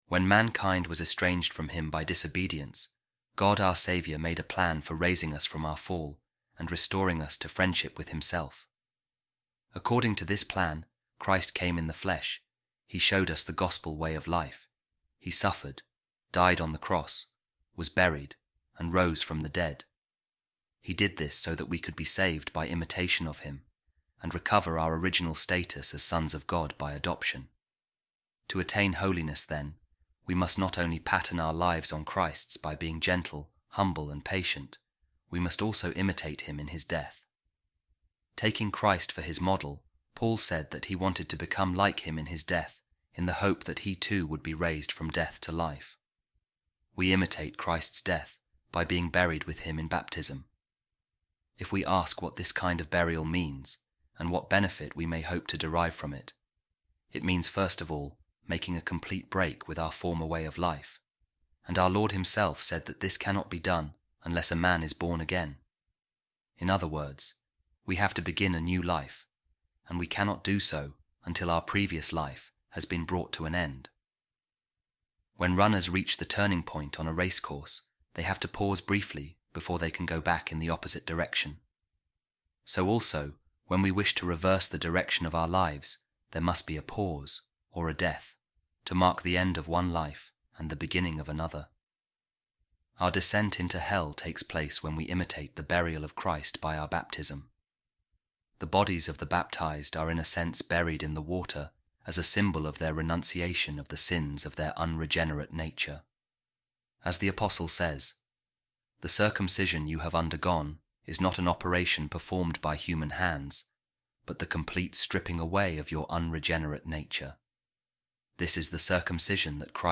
holy-week-tuesday-reading-saint-basil-holy-spirit.mp3